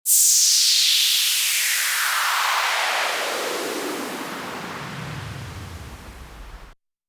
Schweep.wav